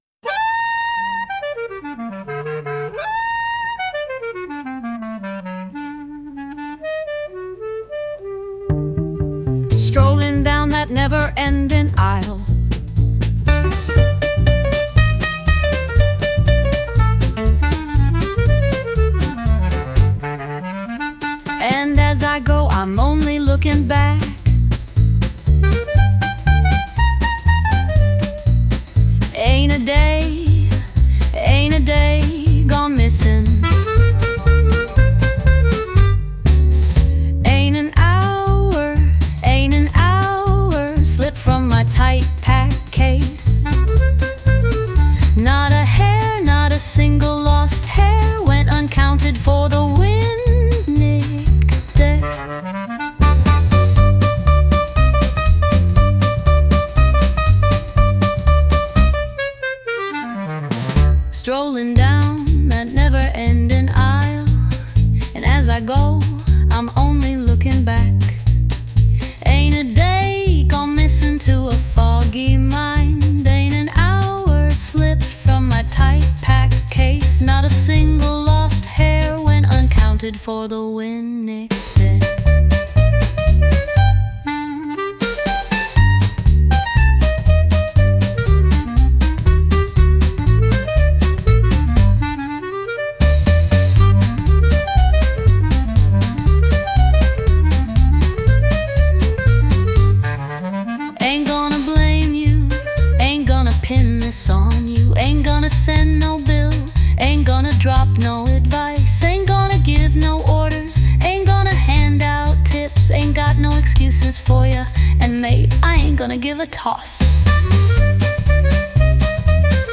With Vocals / 歌あり
1930年代の「古き良き時代」を彷彿とさせる、本格的なジャズ・ナンバー。
特筆すべきは、大人っぽくて素敵な女性ボーカル。
その歌声とサックスの音色が溶け合い、会場を上品でムーディーな空気に包み込みます。